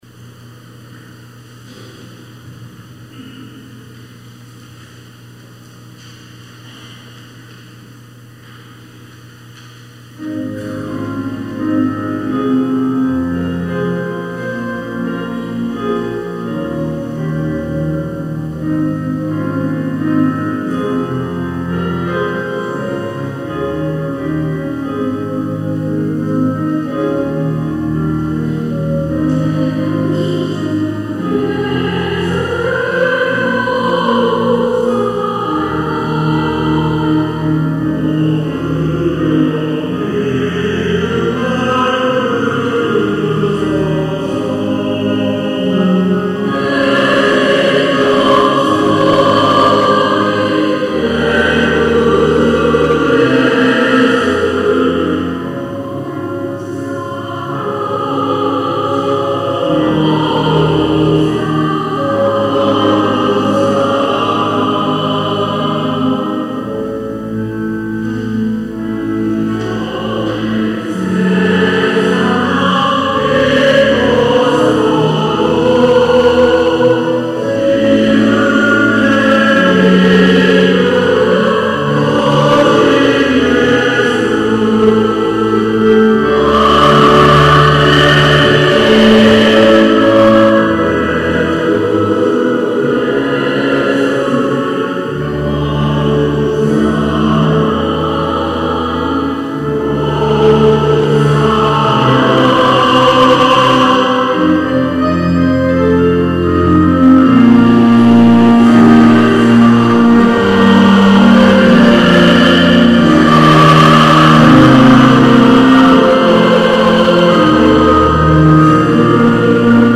2015년 8월 09일 주일 3부예배 찬양
찬양대
오르간
피아노
성가대 찬양